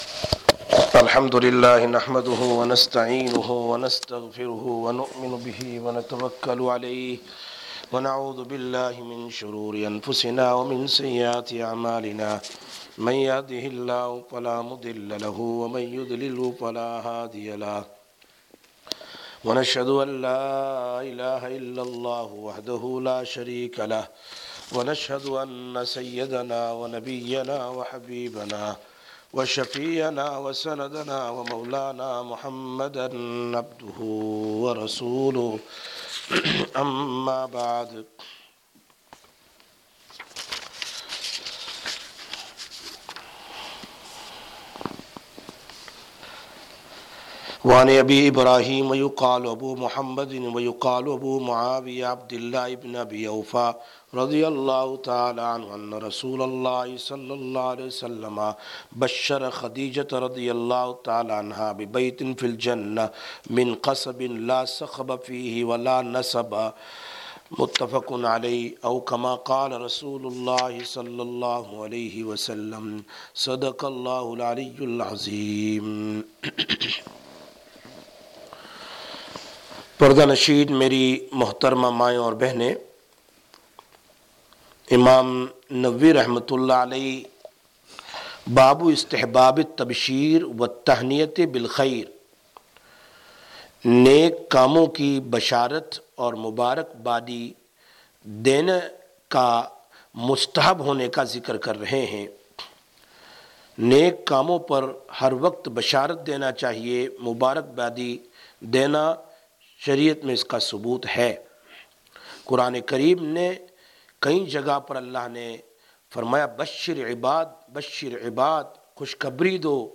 29/01/2020 Sisters Bayan, Masjid Quba